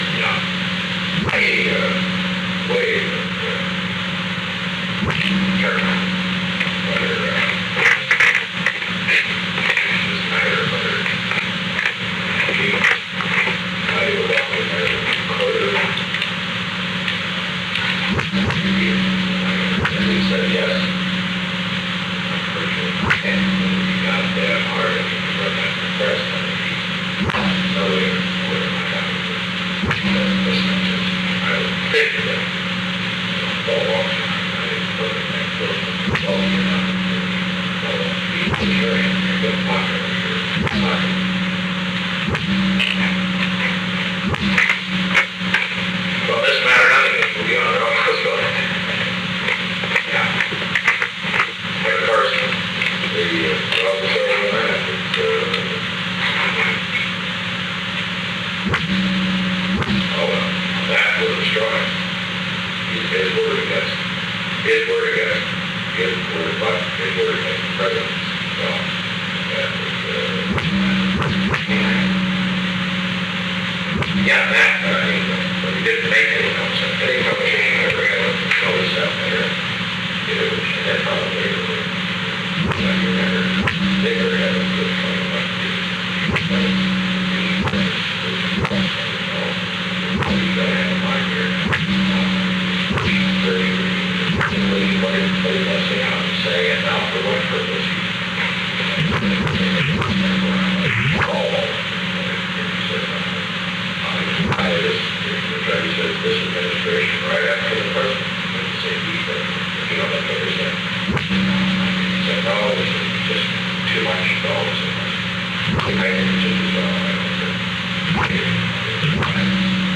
Secret White House Tapes
Location: Executive Office Building
The President talked with H. R. (“Bob”) Haldeman.